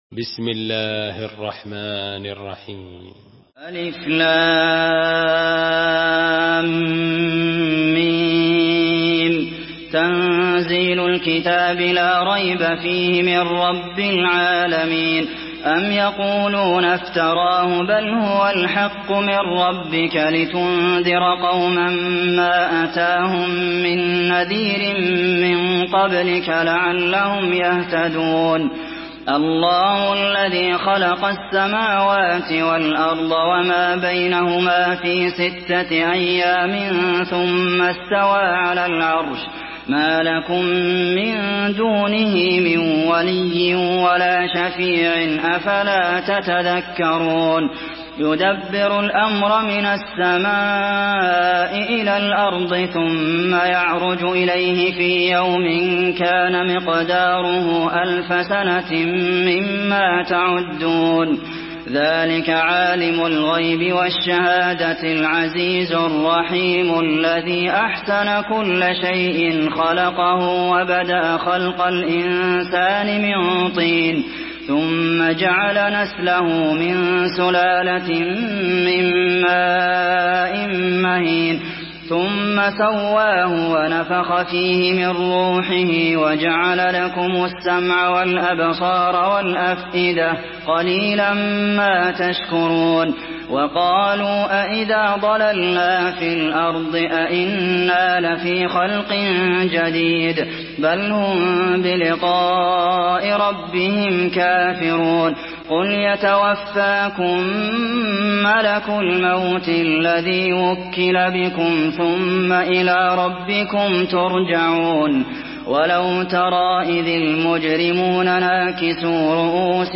Surah As-Sajdah MP3 in the Voice of Abdulmohsen Al Qasim in Hafs Narration
Murattal Hafs An Asim